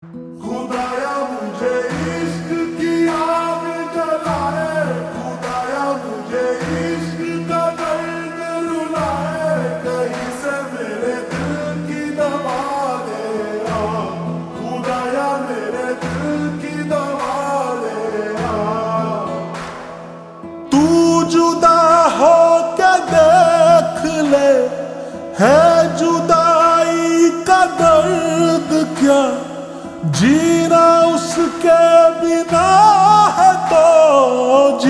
( Slowed + Reverb)